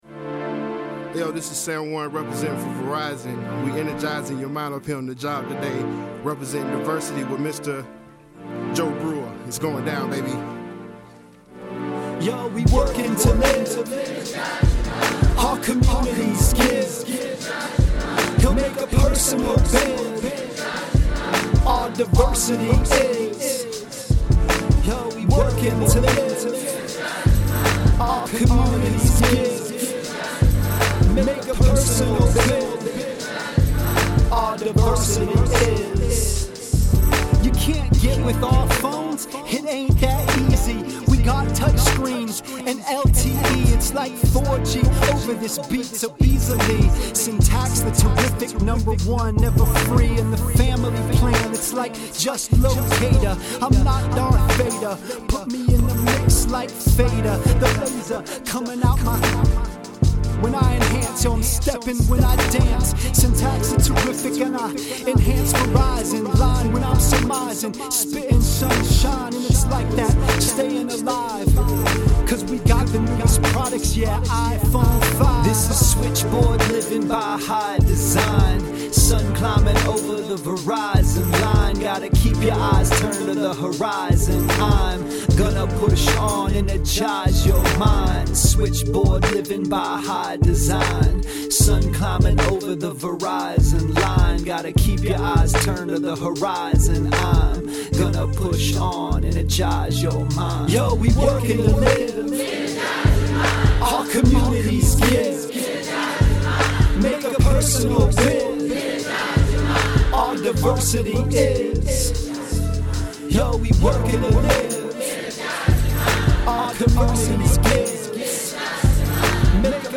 As usual, we recorded a live bridge with the audience and I took relevant topics to freestyle the verses.
Written and recorded live at Verizon Employee Appreciation Week.